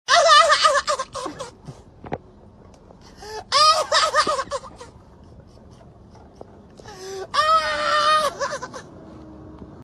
Laughing Dog Meme Sound Effect Free Download
Laughing Dog Meme